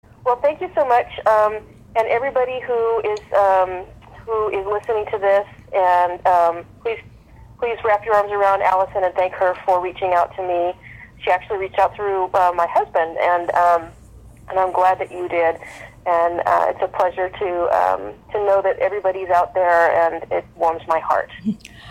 Audio Greeting